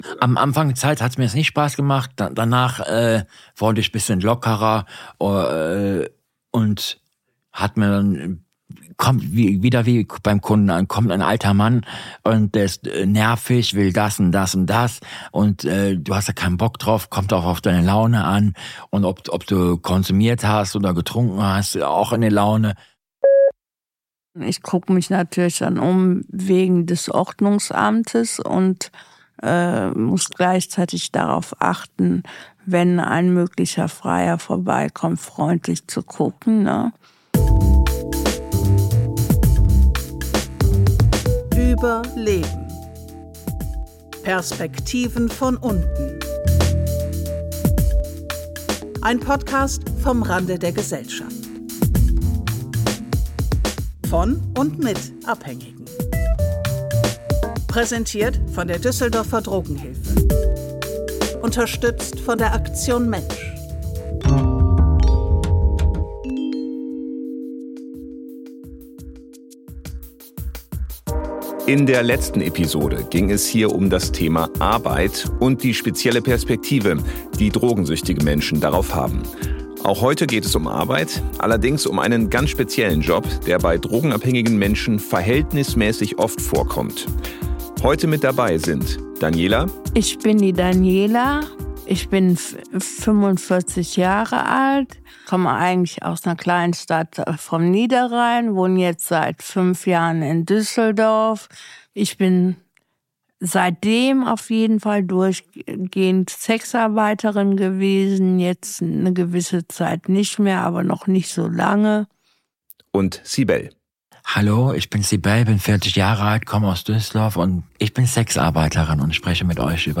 In dieser Folge berichten zwei Sexarbeiterinnen über ihren Job. Sie erzählen außerdem von gefährlichen Situationen mit Freiern und was diese Arbeit mit ihnen gemacht hat.